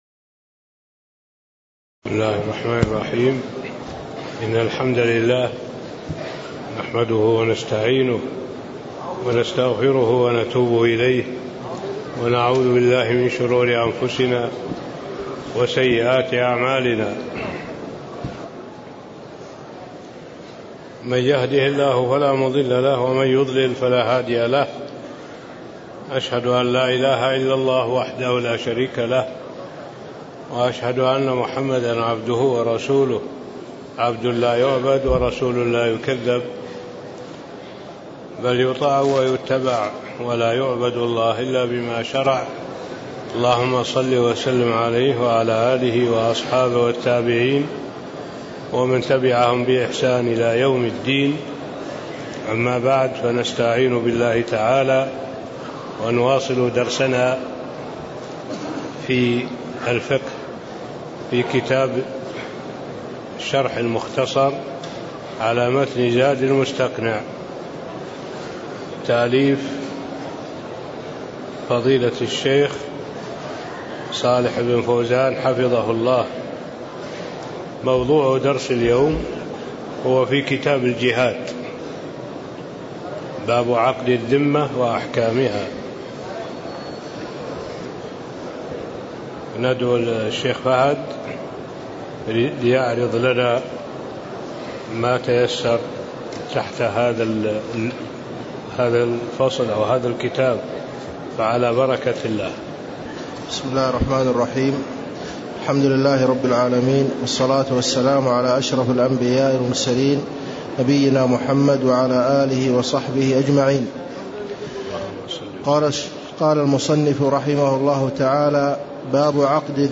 تاريخ النشر ٦ محرم ١٤٣٥ هـ المكان: المسجد النبوي الشيخ: معالي الشيخ الدكتور صالح بن عبد الله العبود معالي الشيخ الدكتور صالح بن عبد الله العبود عقد الذمّه وأحكامها (01)